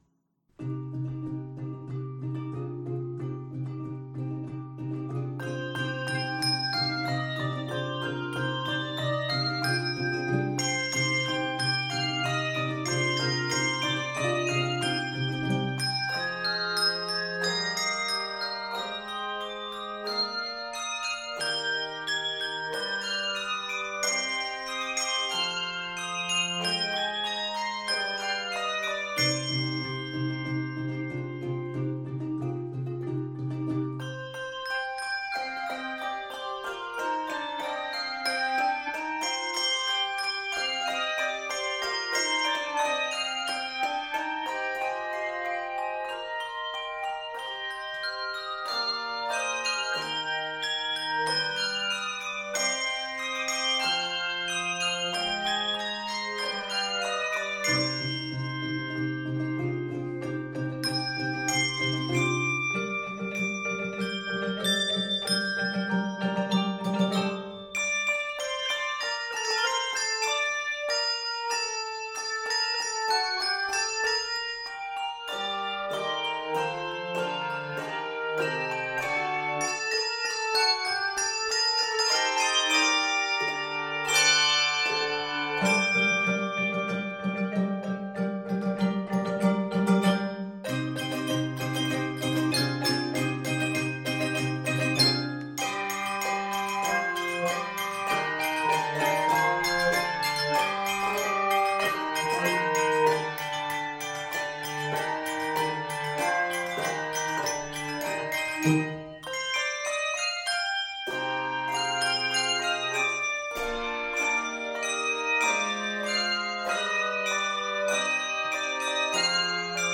Keys of c minor and g minor.